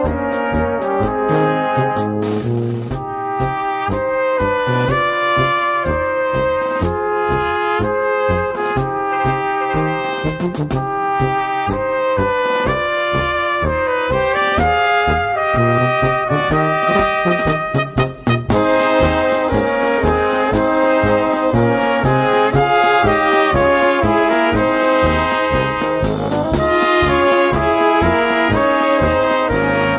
Gattung: Kleine Besetzung
B Besetzung: Kleine Blasmusik-Besetzung Tonprobe